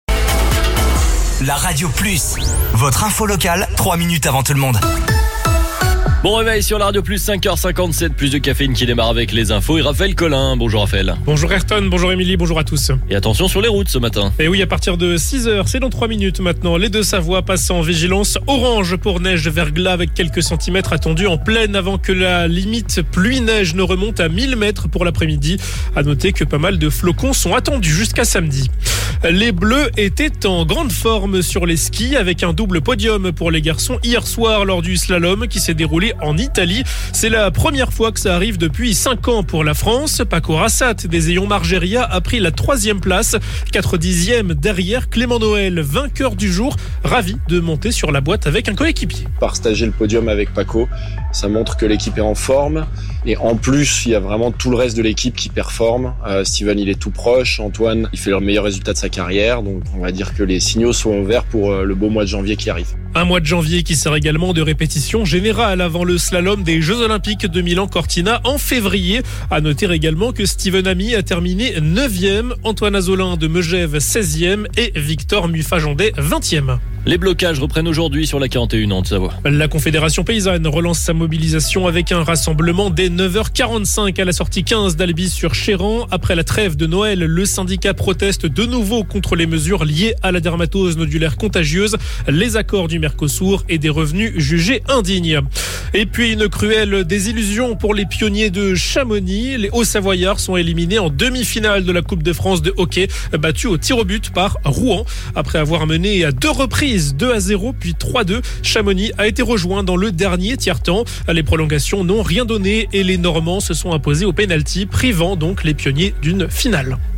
Votre flash info - votre journal d'information sur La Radio Plus